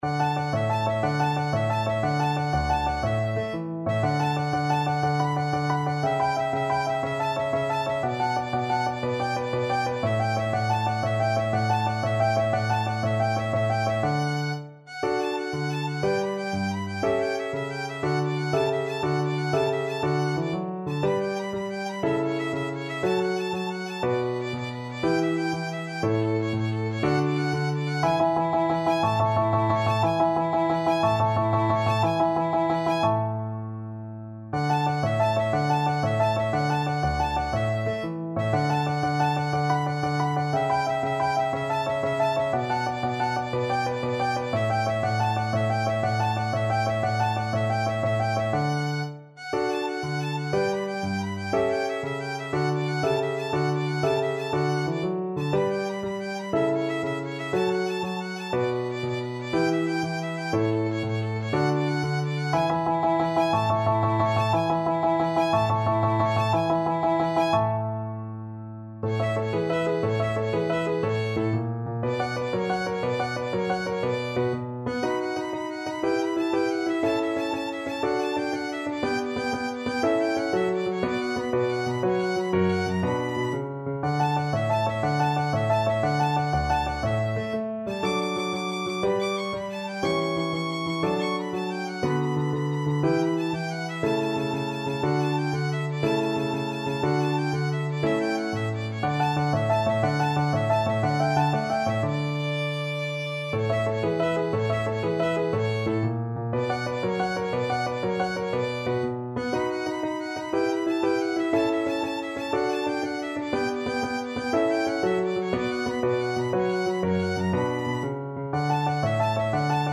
12/8 (View more 12/8 Music)
. = 120 Allegro (View more music marked Allegro)
Classical (View more Classical Violin Music)